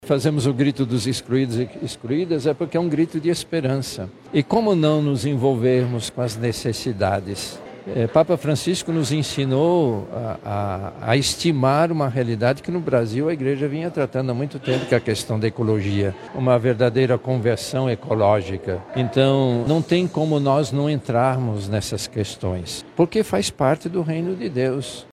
Em uma coletiva de imprensa realizada na manhã desta terça-feira, 26 de agosto, no auditório da Cúria Metropolitana, a Arquidiocese de Manaus apresentou a 31° edição do Grito dos Excluídos e Excluídas.
SONORA-1-CARDEAL.mp3